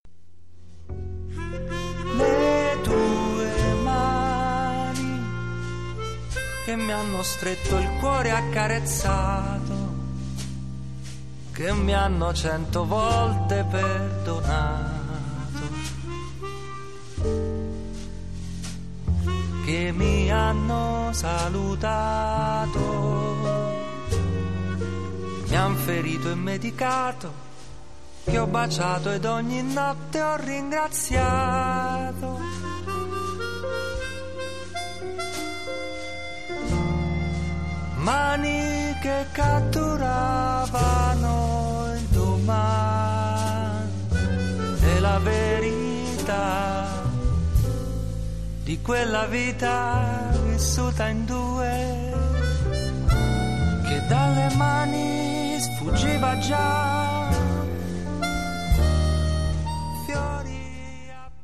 piano e voce
contrabbasso
batteria
o con quella bella e raffinata canzone quale è